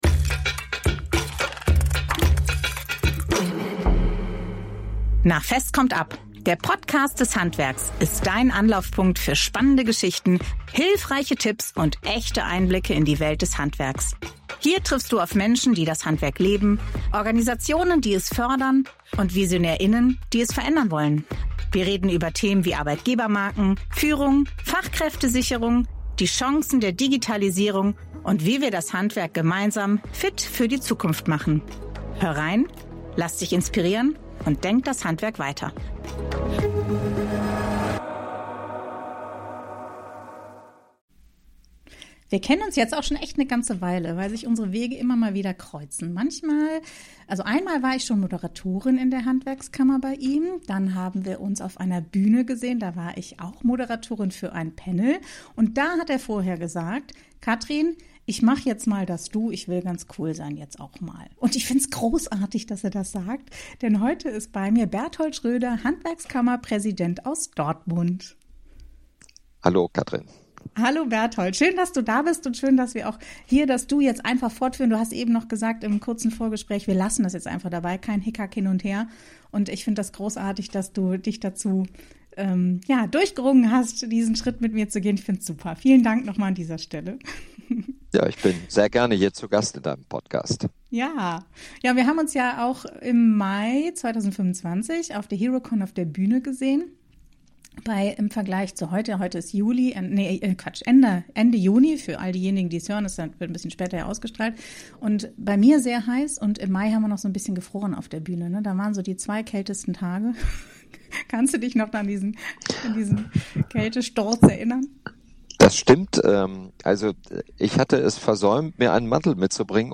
Ein Gespräch über Freiheit, Führung, Fehlentscheidungen und den Mut, den eigenen Weg zu gehen.